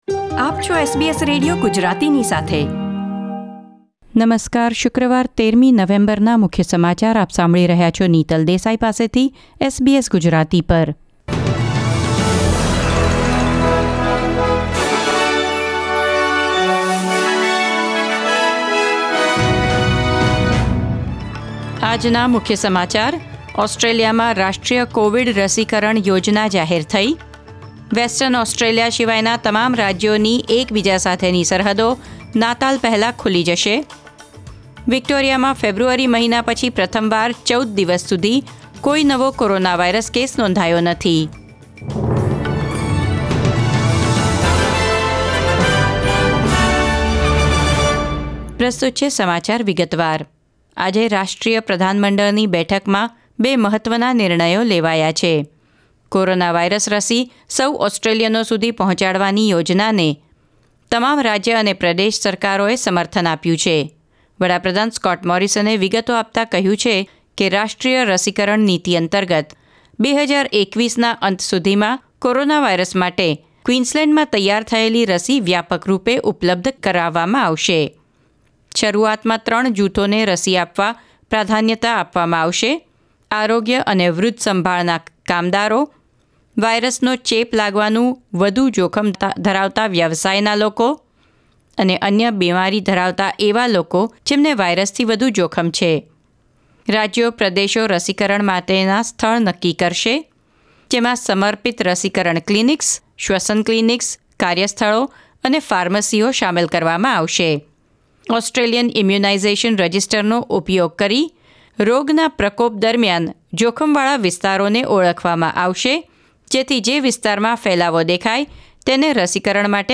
SBS Gujarati News Bulletin 13 November 2020